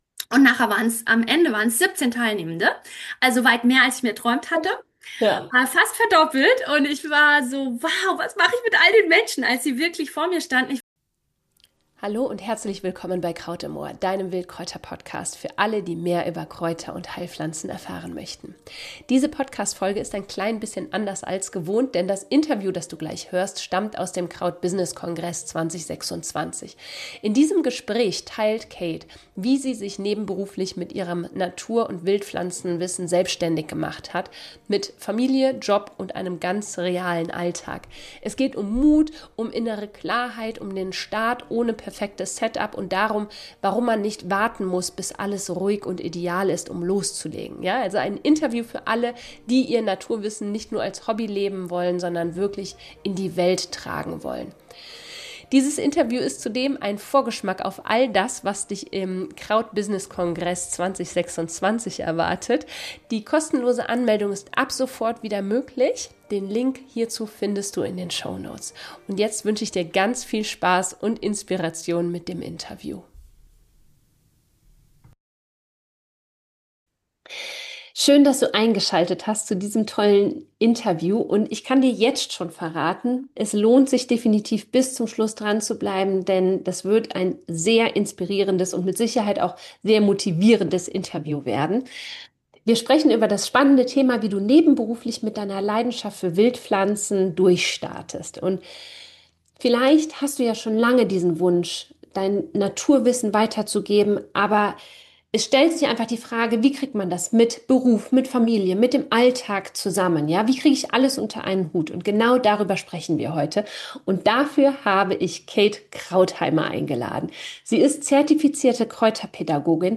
Kraut-Interview: Wildpflanzen als Nebenberuf - so startest du ~ Kraut im Ohr - Dein Wildkräuter Podcast